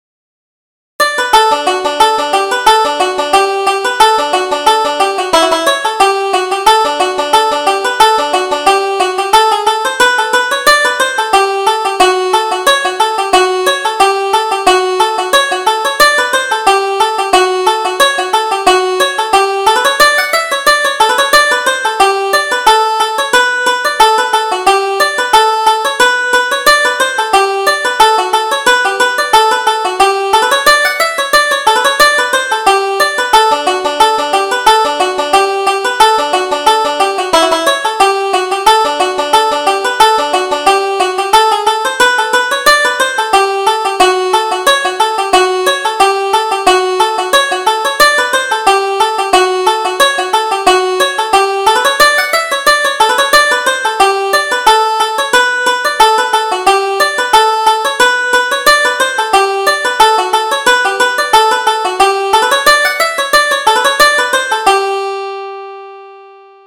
Reel: The Four Courts - 1st Setting